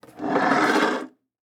ES_Chair Plastic 1 - SFX Producer.wav